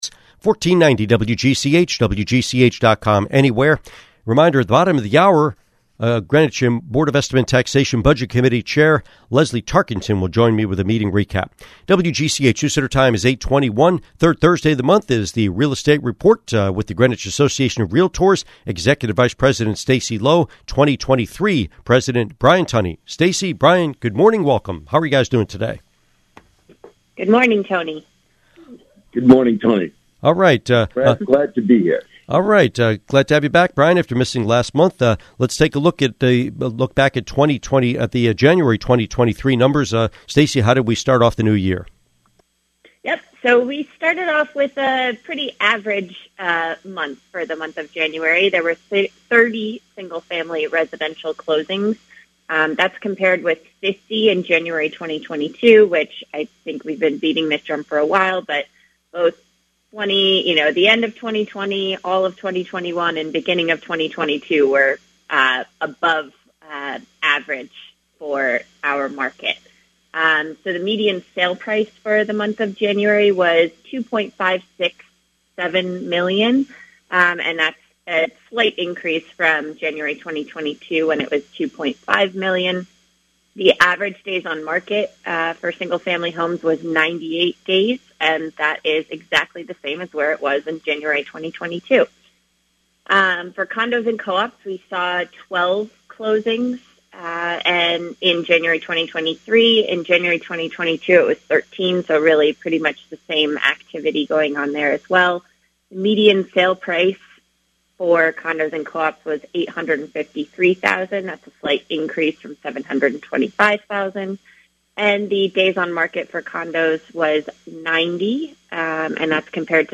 Interview with State Senator Ryan Fazio